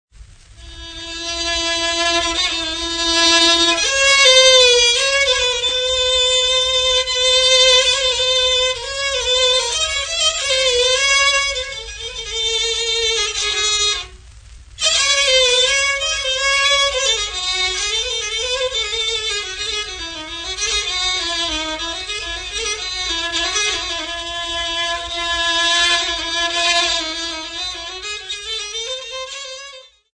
Folk Music
Field recordings
Africa Tanzania City not specified f-tz
sound recording-musical
Indigenous music
Vestax BDT-2500 belt drive turntable